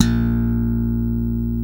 Index of /90_sSampleCDs/Roland - Rhythm Section/BS _E.Bass 1/BS _5str v_s